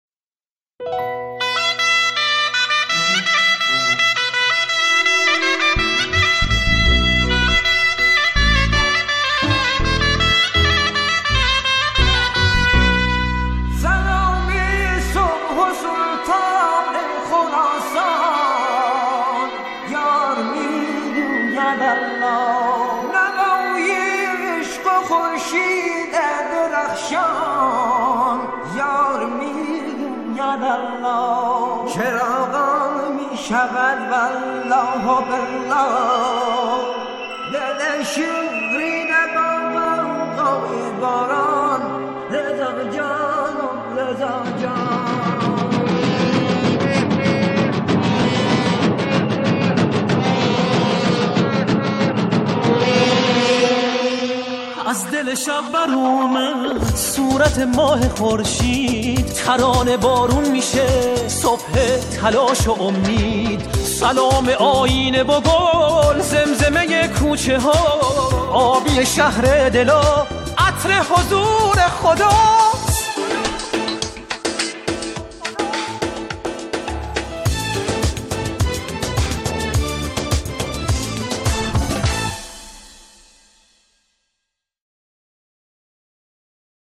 سرودهای امام رضا علیه السلام